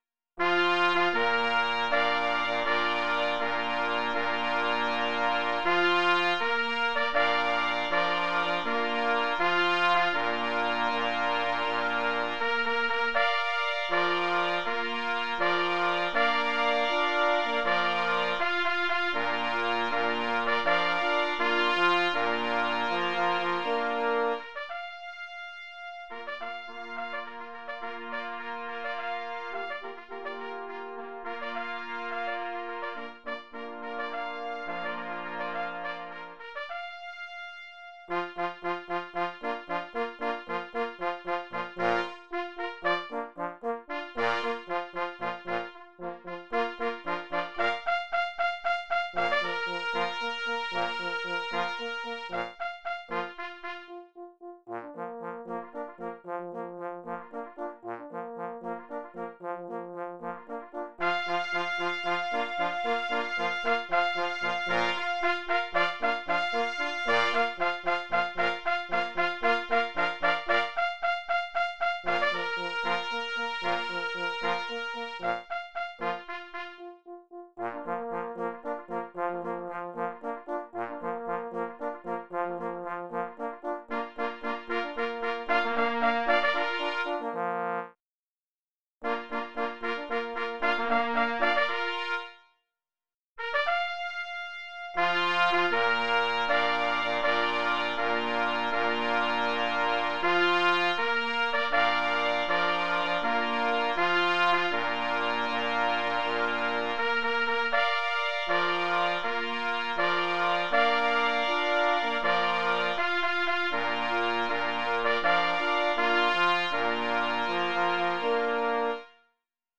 utwór na 3 plesy i 2 parforsy